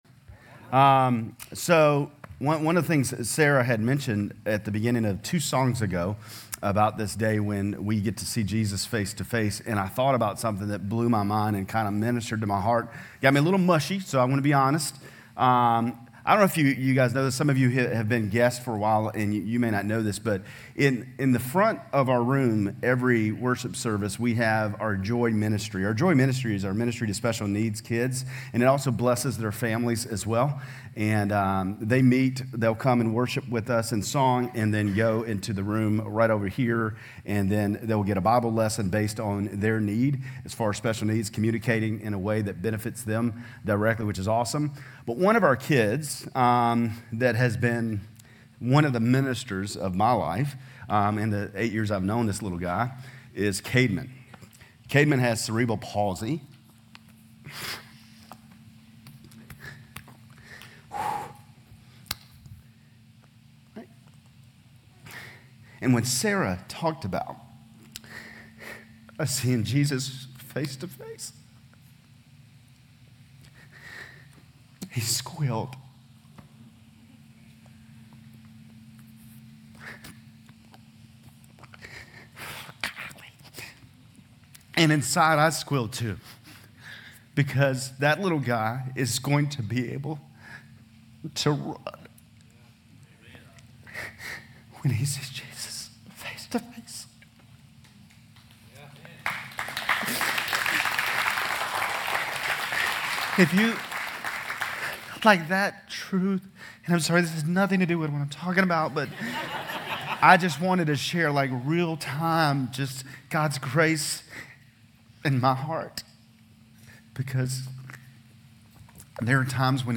GCC-Lindale-September-24-Sermon.mp3